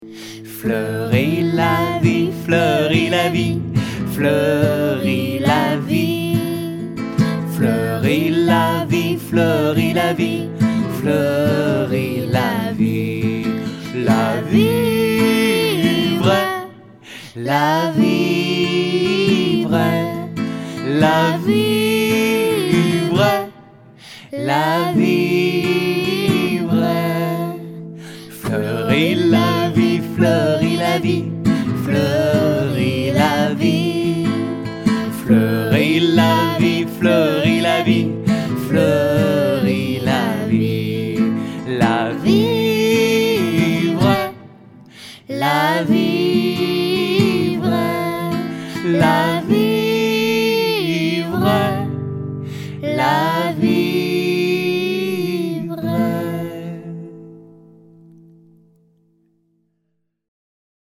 Voici quelques chants pratiqués en atelier.